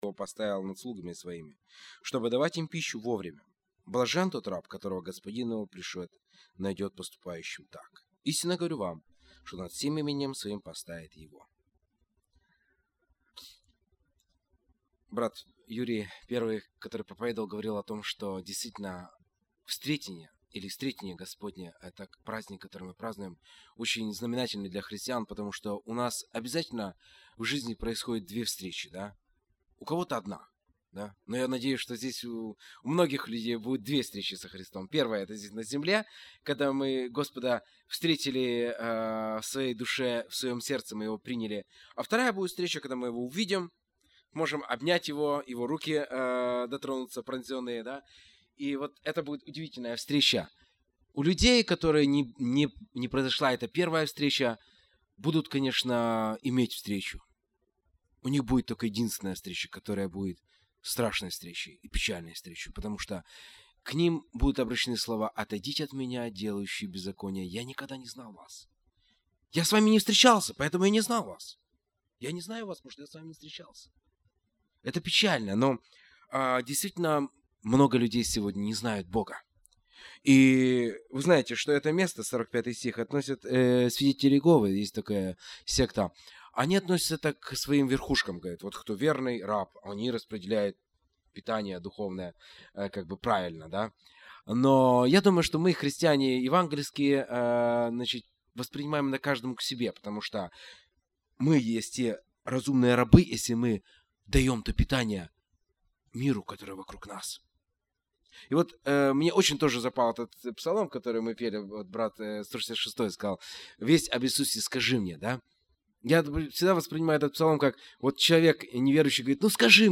Воскресные Богослужения